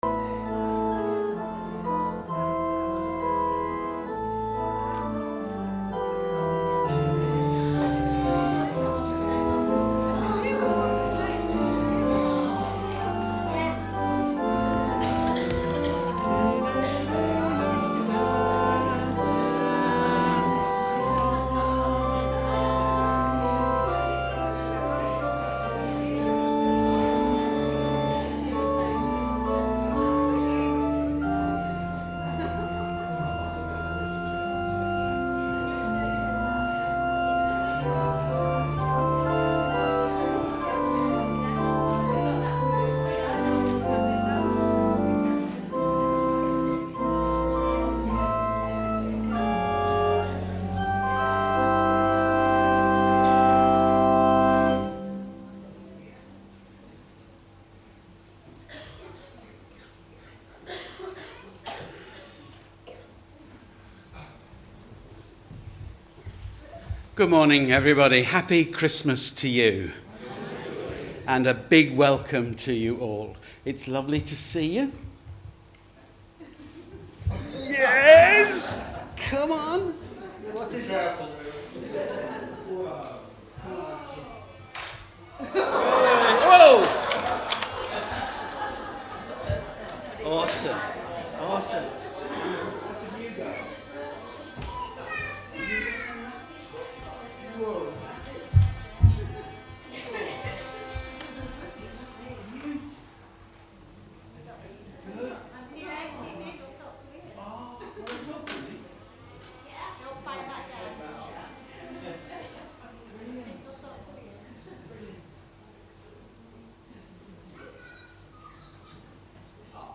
Please join us in spirit and listen to our 3 Christmas services.